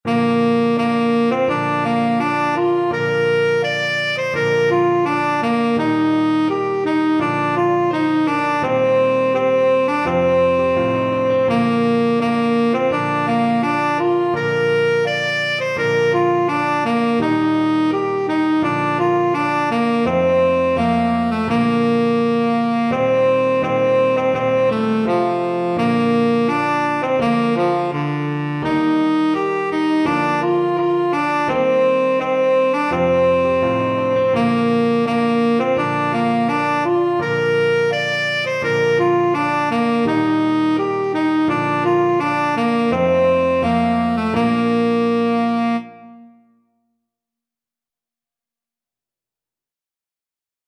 Tenor Saxophone
Bb major (Sounding Pitch) C major (Tenor Saxophone in Bb) (View more Bb major Music for Tenor Saxophone )
Molto energico =c.84
2/2 (View more 2/2 Music)
Scottish